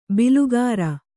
♪ bilugāra